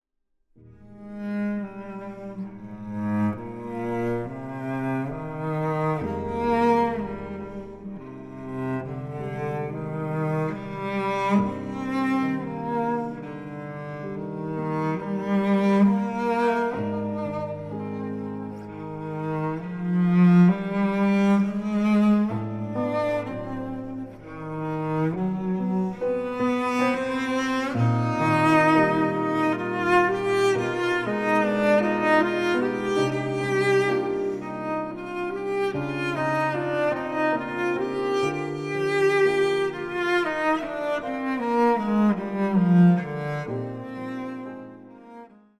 チェロのたおやかな音色による実直な美の結晶であること。
チェロ
ピアノ